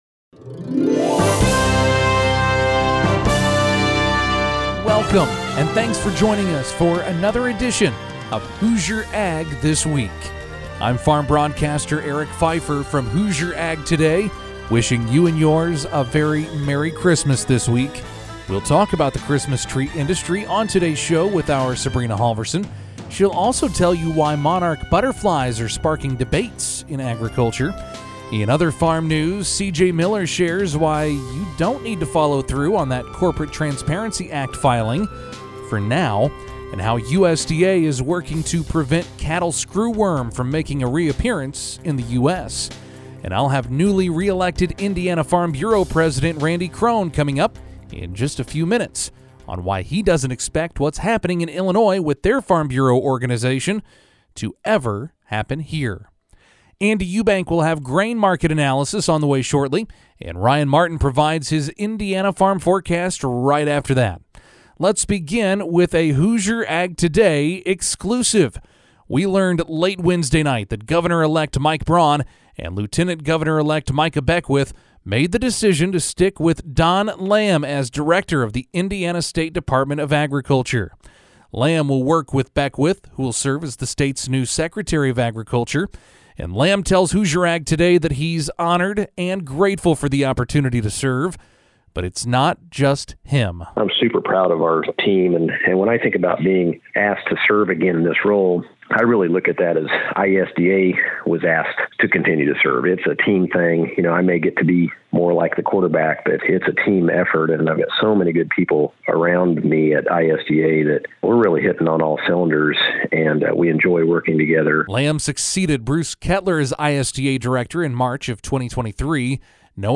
In dieser kompakten 30-minütigen Folge tauchen Sie ein in die Welt der Generative Engine Optimization (GEO). Unsere zwei KI-Hosts, erstellt mit NotebookLM, fassen die wichtigsten Erkenntnisse aus dem gleichnamigen Amazon-…